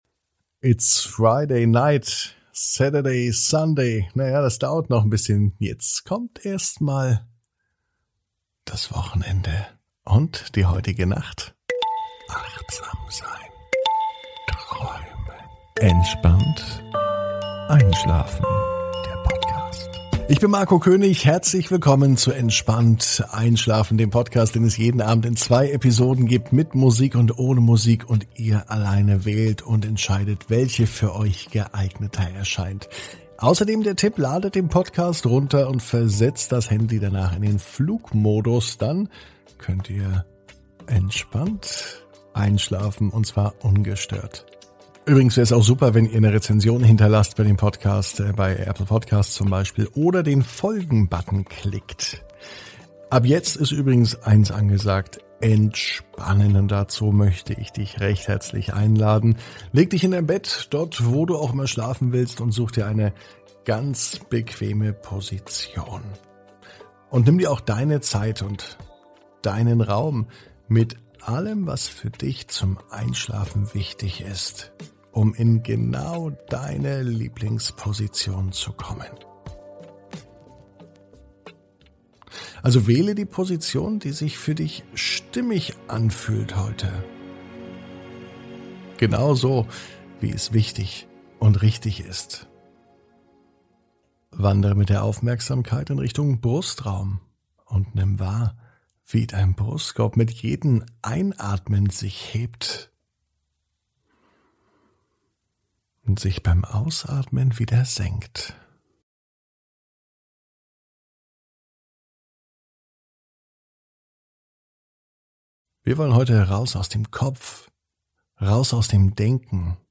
(ohne Musik) Entspannt einschlafen am Freitag, 14.05.21 ~ Entspannt einschlafen - Meditation & Achtsamkeit für die Nacht Podcast